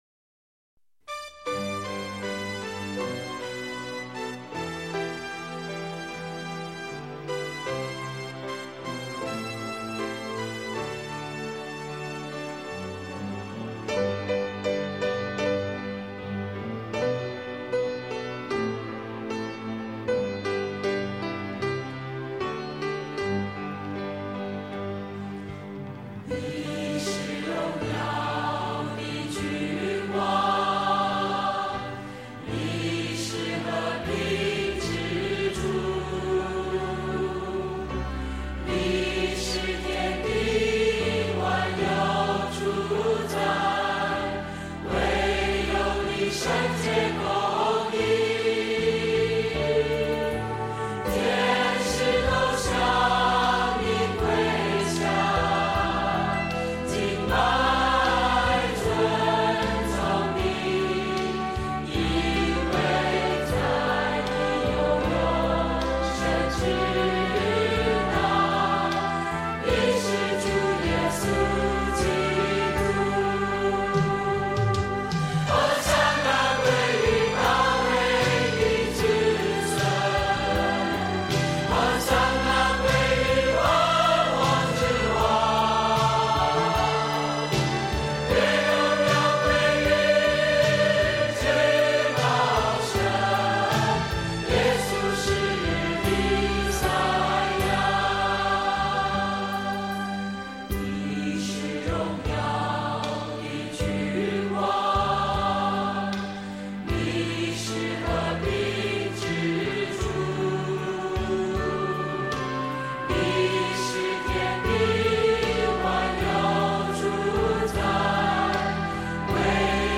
赞美诗《你是荣耀君王》